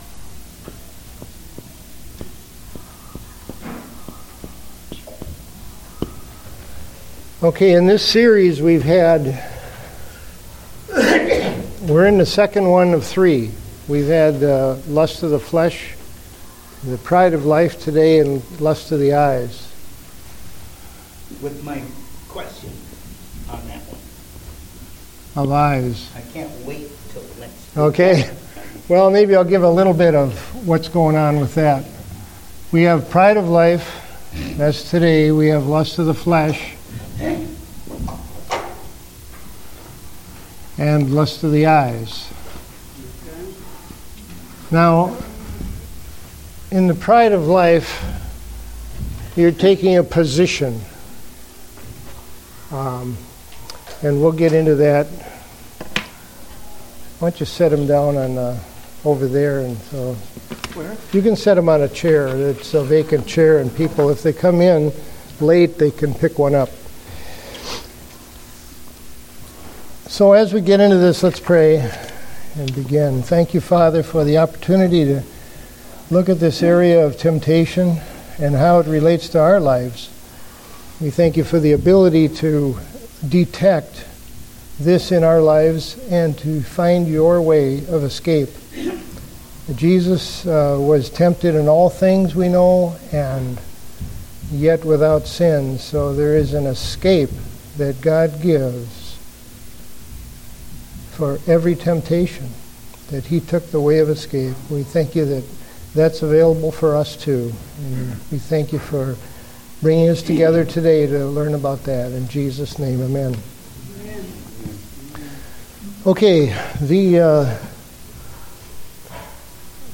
Date: February 22, 2015 (Adult Sunday School)